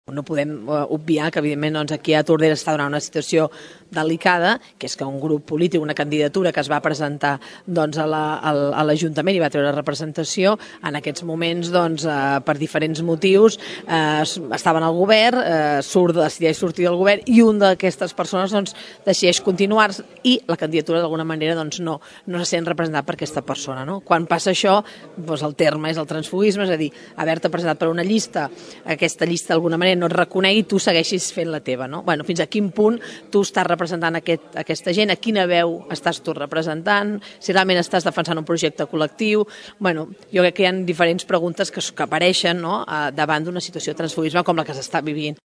En declaracions a Ràdio Tordera, Romero defineix com a delicada la situació política al nostre municipi i es planteja diverses qüestions.